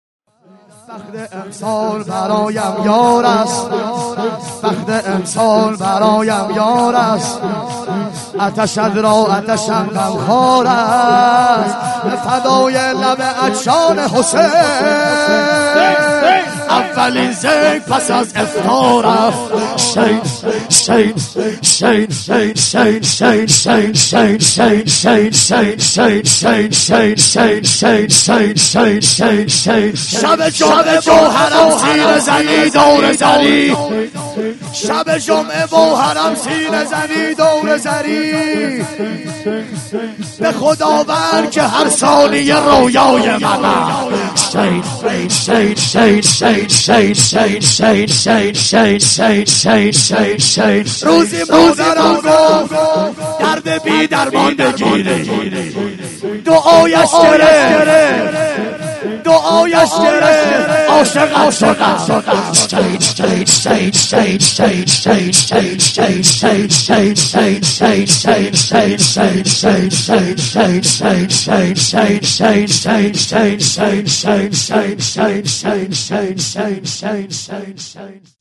11شعرخوانی.mp3